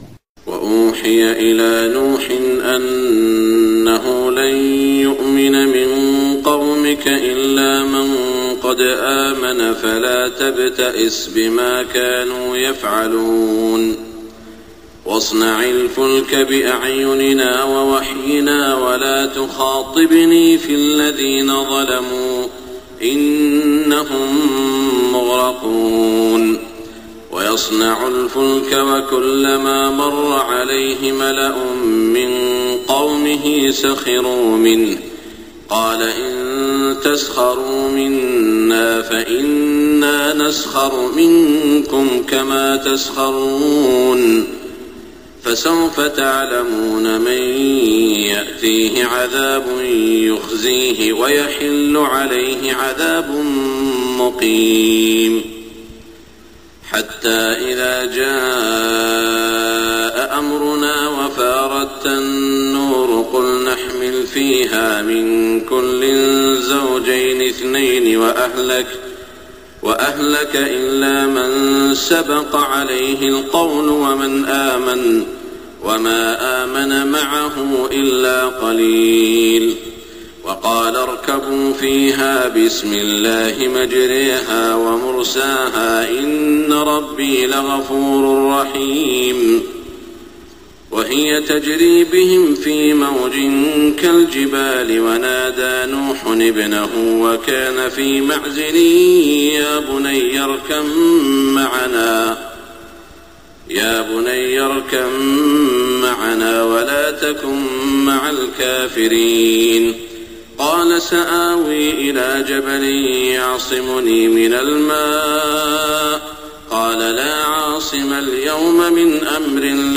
صلاة الفجر 5-1426 من سورة هــود > 1426 🕋 > الفروض - تلاوات الحرمين